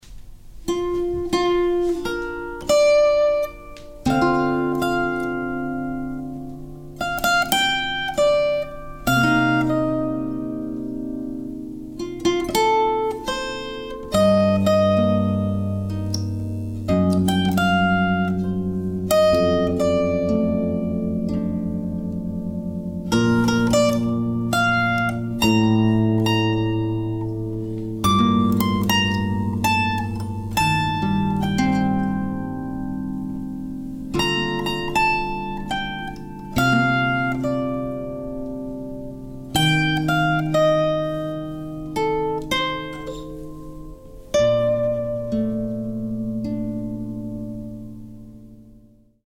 91.-Cytry.mp3.mp3